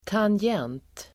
Uttal: [tanj'en:t el. tangg'en:t]